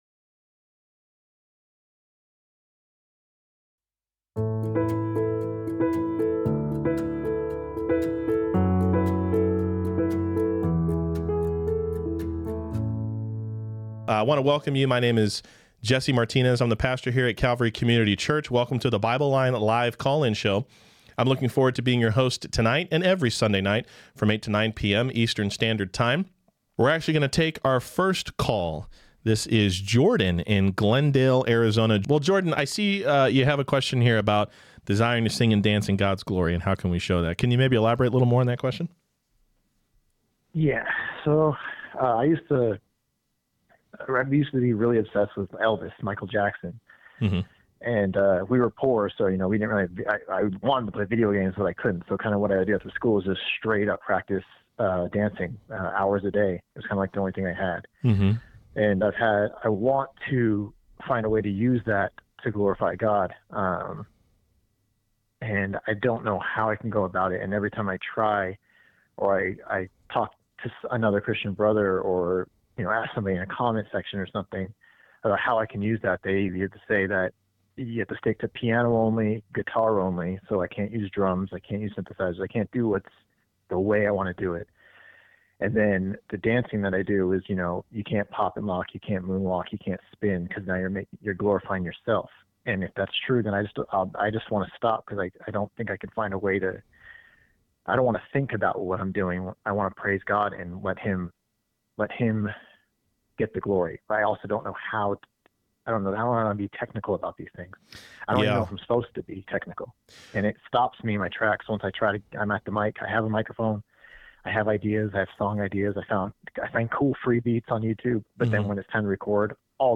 BibleLine LIVE QNA Replay | Dancing, Universalism, OSAS, Pets, Fruit, and more!!!